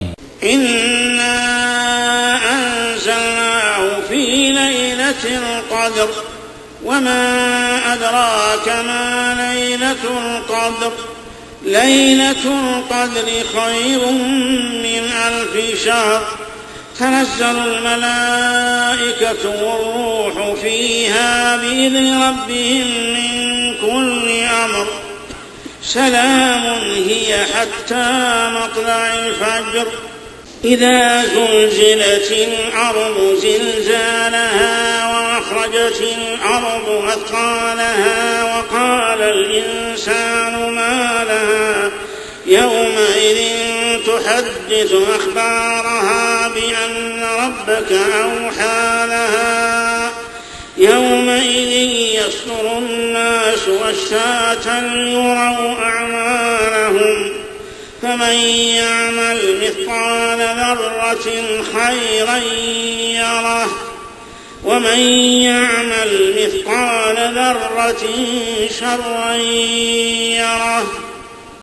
عشائيات شهر رمضان 1426هـ سورة القدر و الزلزلة كاملة | Isha prayer Surah Al-Qadr and Az-Zalzalah > 1426 🕋 > الفروض - تلاوات الحرمين